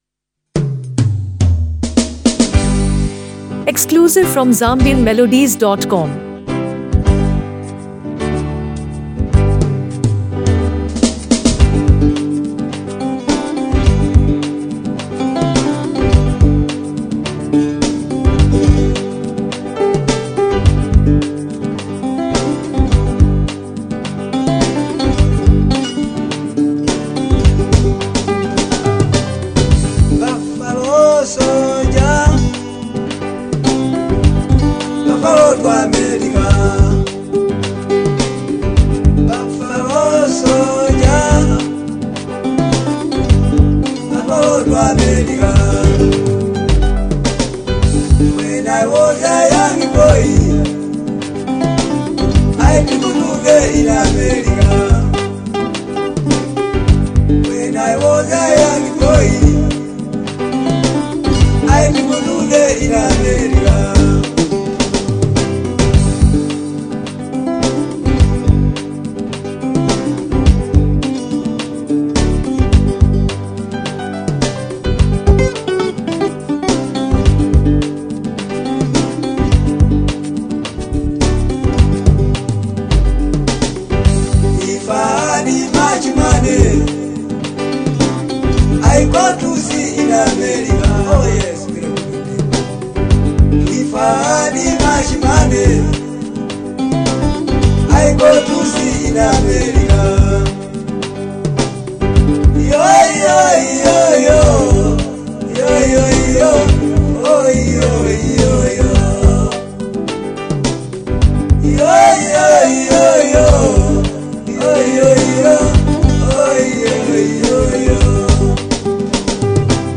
warm vocals